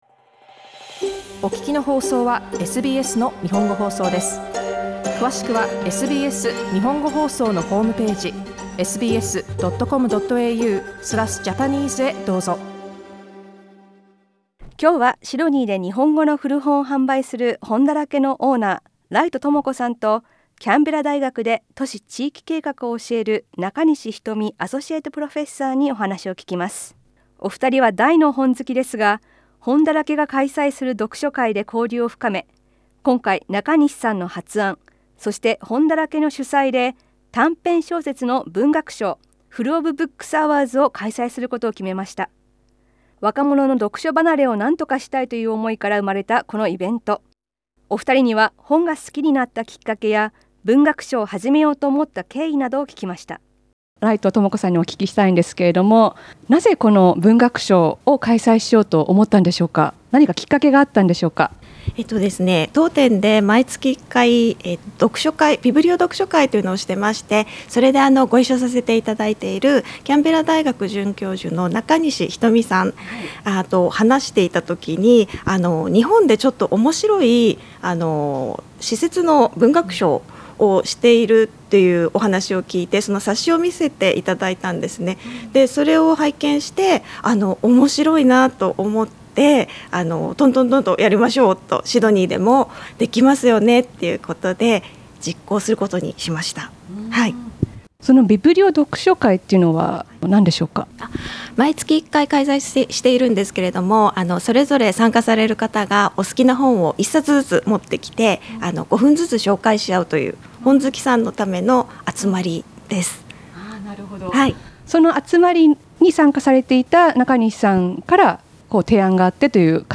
インタビューでは、２人が本が好きになったきっかけや、文学賞を始めることになった経緯などを聞きました。